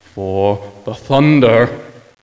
forThunder2.mp3